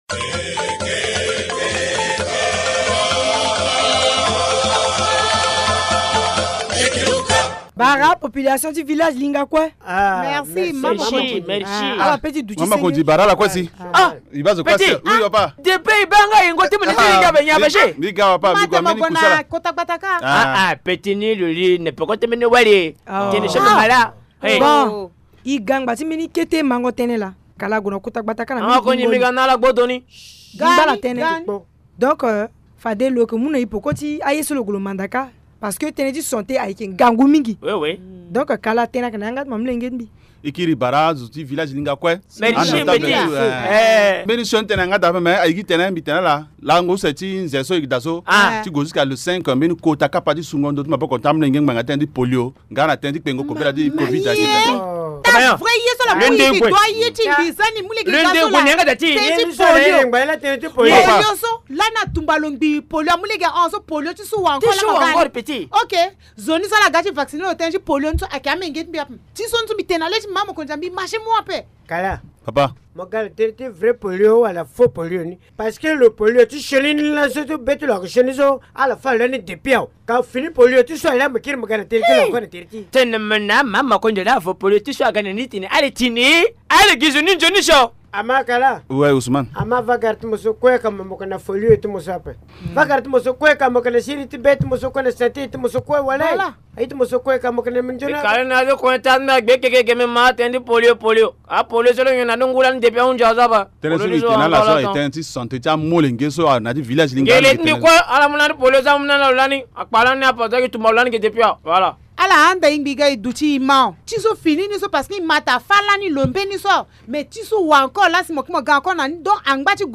Linga théâtre : les sages du village lancent une vaste campagne contre la poliomyélite